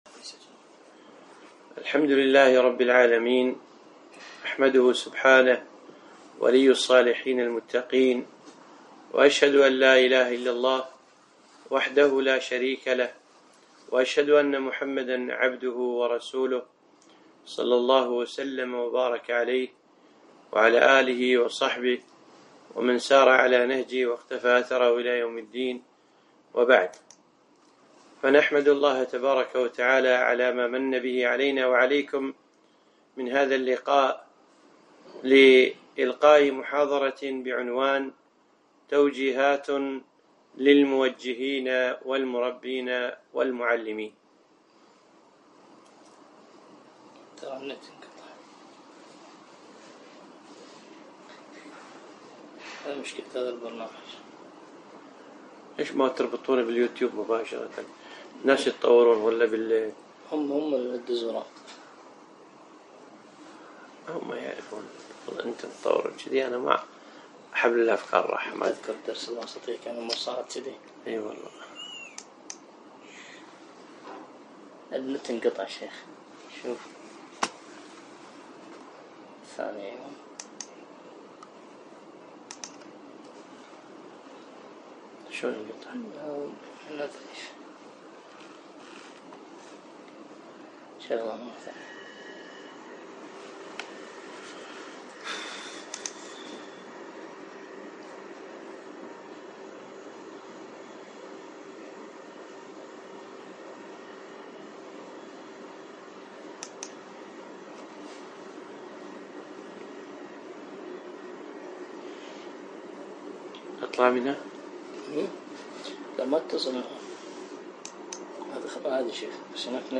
محاضرة - توجيهات للموجهين والمربين والمعلمين